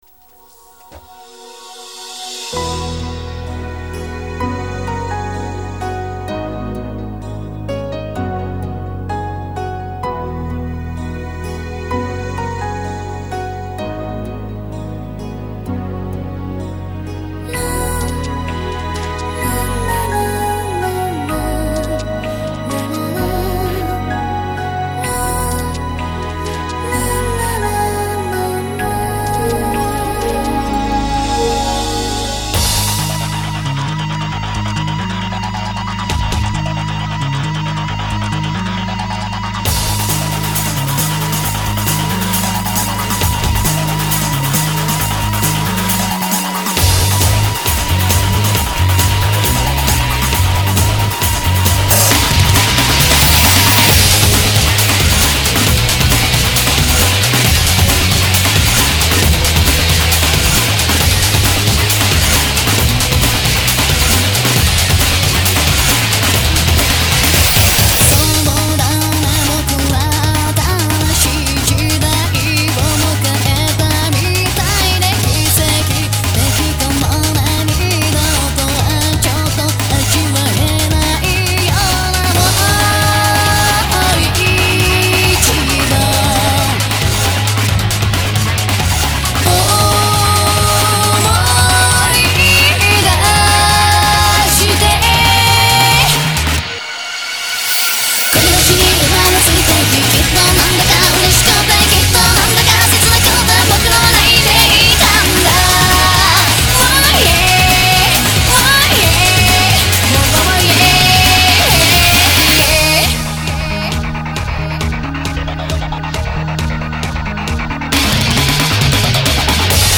fast and upbeat